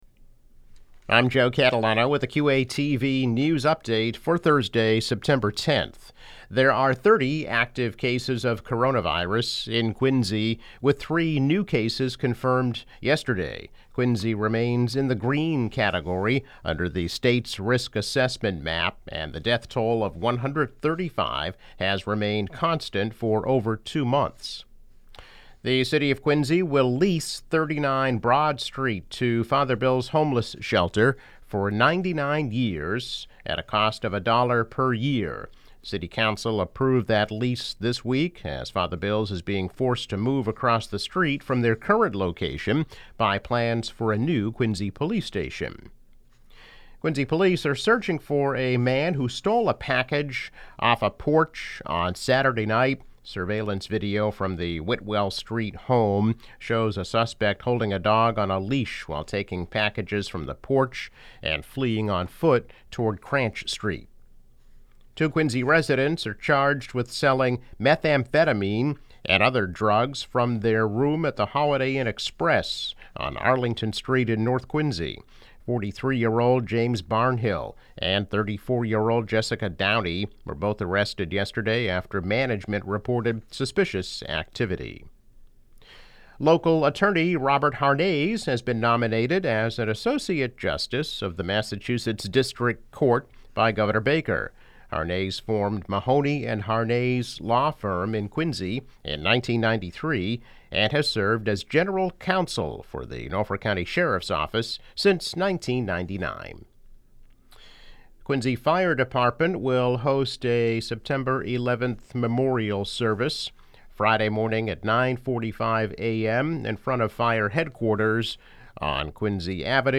News Update - September 10, 2020